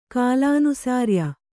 ♪ kālānusārya